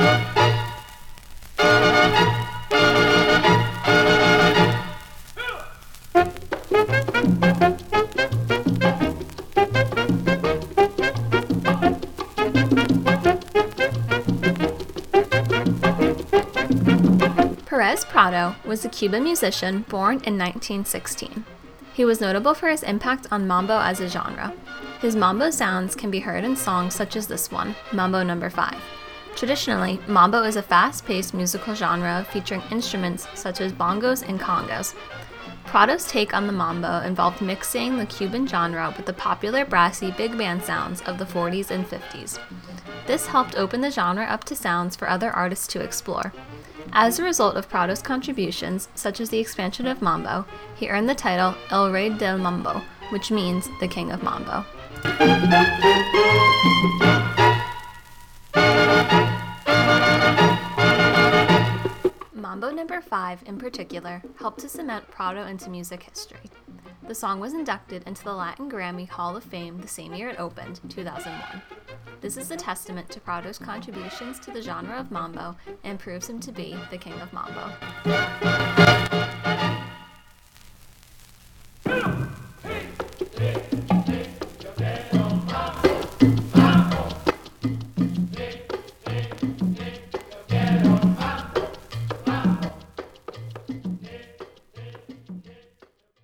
He was notable for his impact on mambo as a genre.